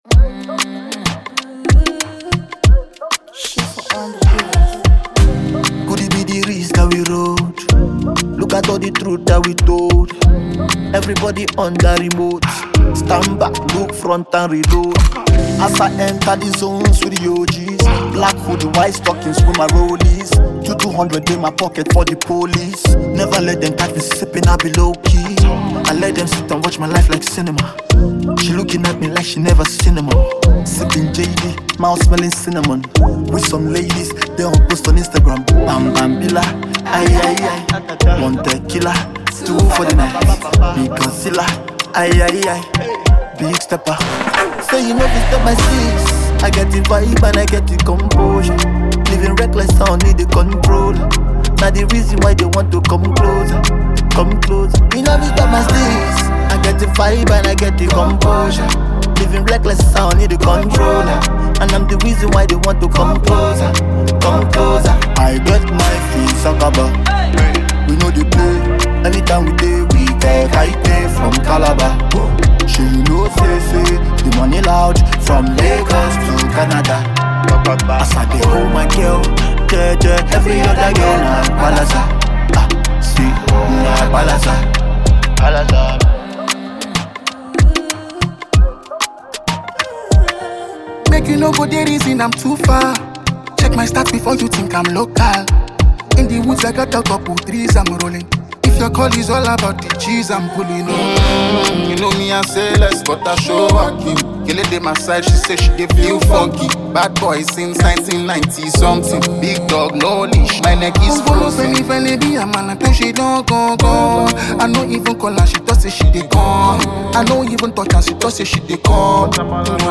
is a fusion of Afrobeat rhythms and infectious melodies